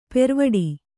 ♪ pervaḍi